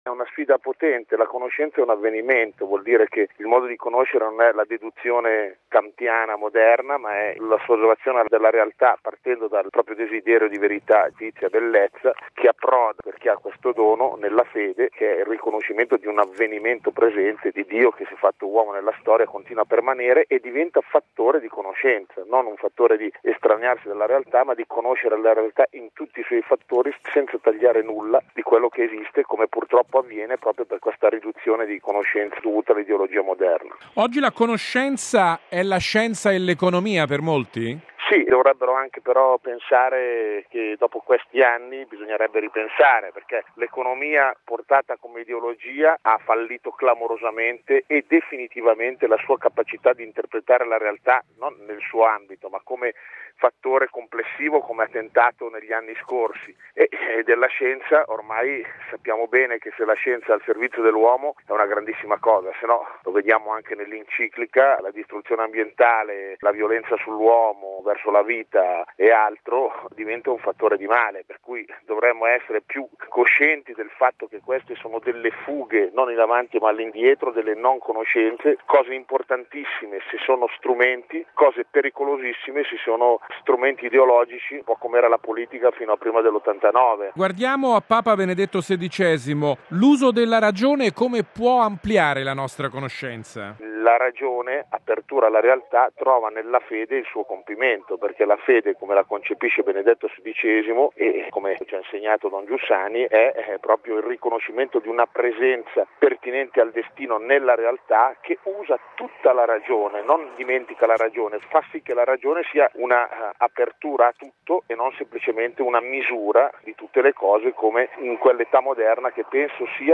A Rimini la 30.ma edizione del Meeting di Cl incentrato sul rapporto tra fede e conoscenza. Intervista